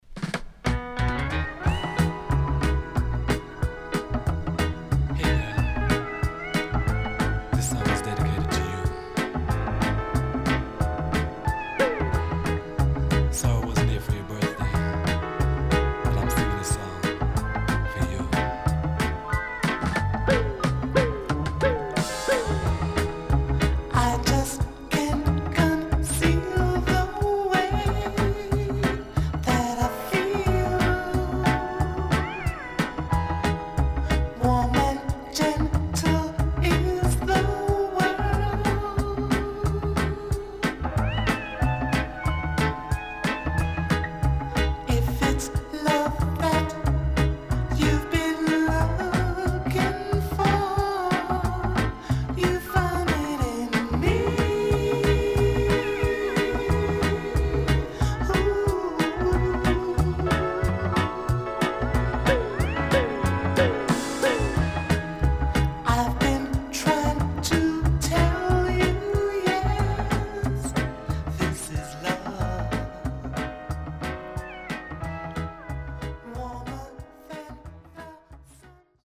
Soulfull Lovers & RADIO.D.J.VERSION.Good Condition
SIDE A:少しチリノイズ入りますが良好です。